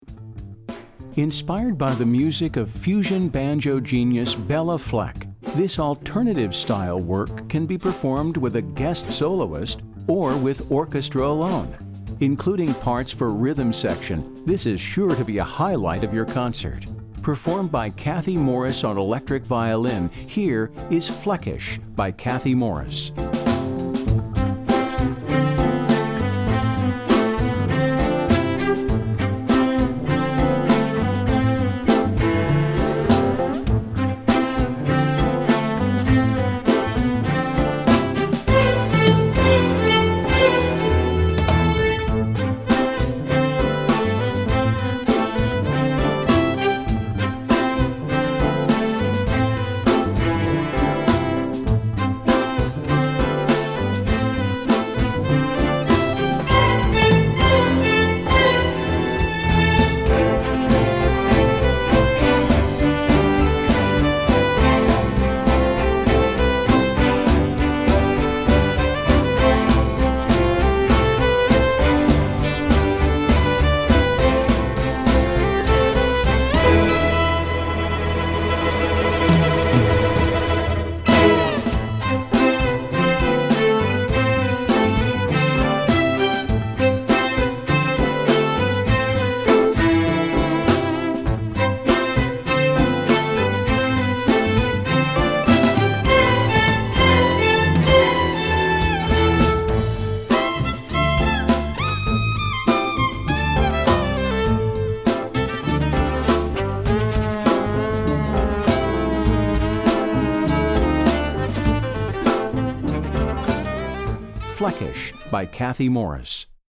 Voicing: Violin and Orch